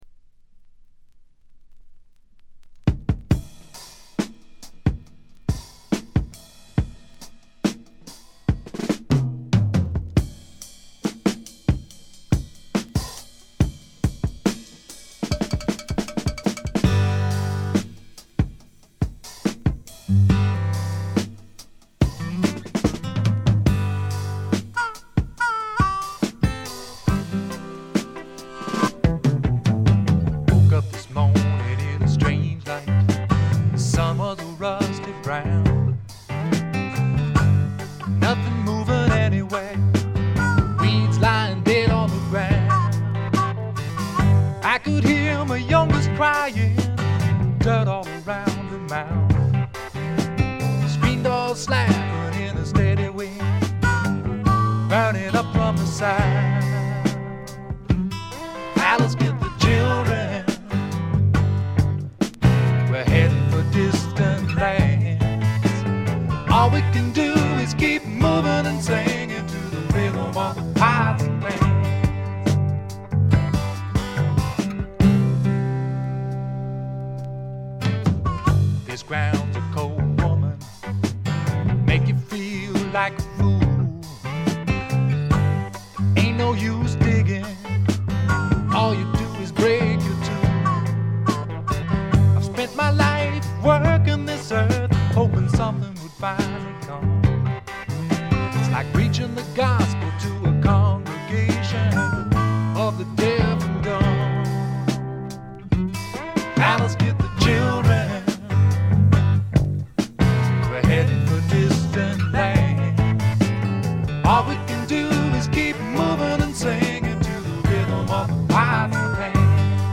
ごくわずかなノイズ感のみ。
シンプルなバックに支えられて、おだやかなヴォーカルと佳曲が並ぶ理想的なアルバム。
試聴曲は現品からの取り込み音源です。